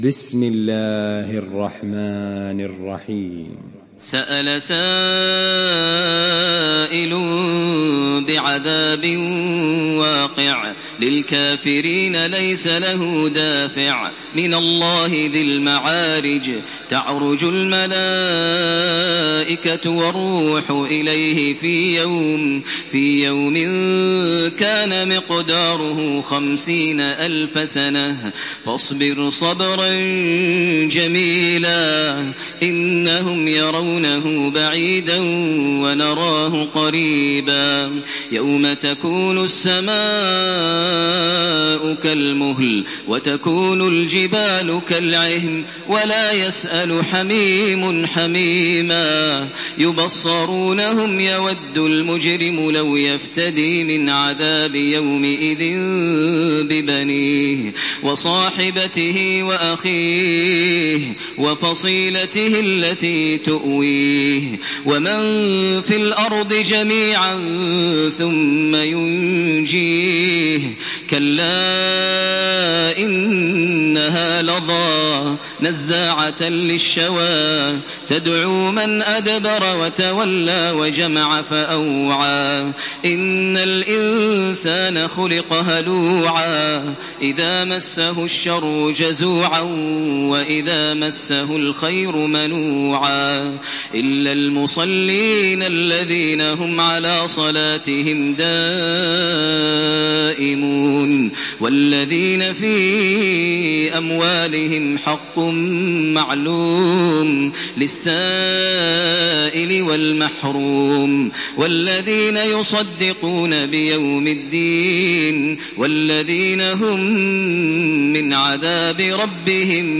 Surah Al-Maarij MP3 in the Voice of Makkah Taraweeh 1428 in Hafs Narration
Murattal